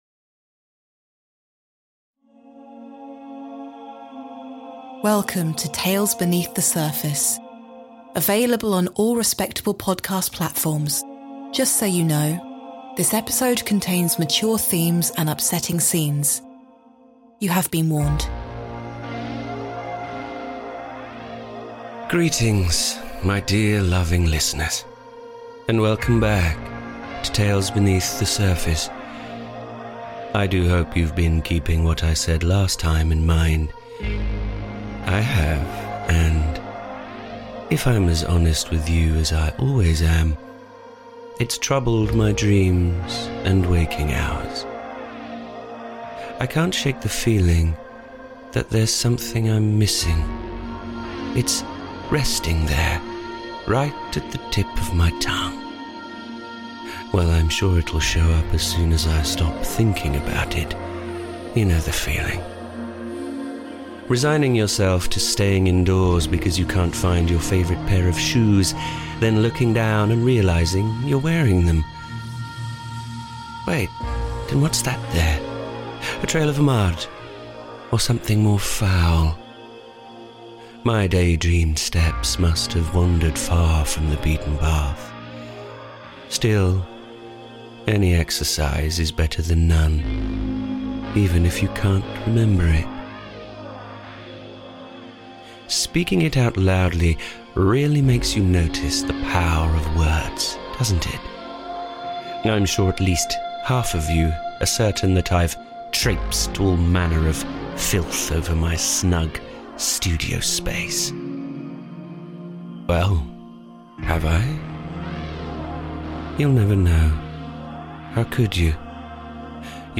Through hiss and distortion, listening becomes and act of reconstruction, and perhaps an invitation.